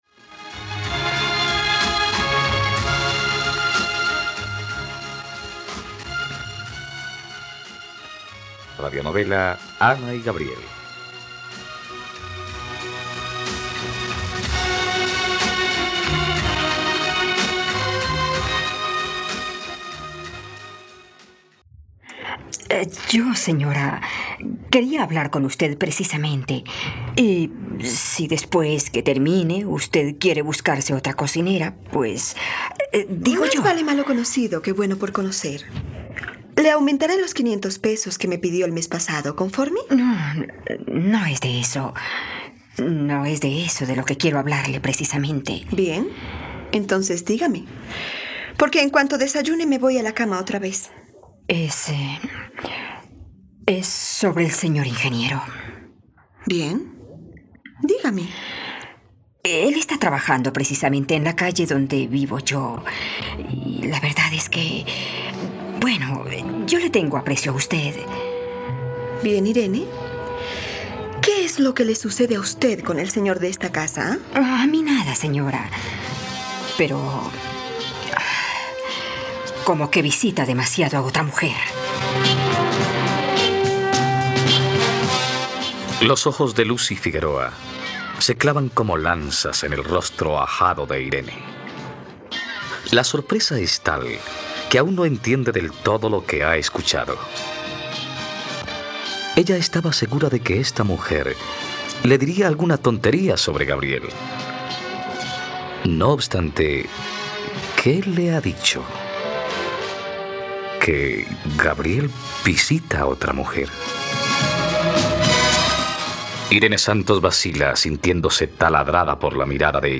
Ana y Gabriel - Radionovela, capítulo 39 | RTVCPlay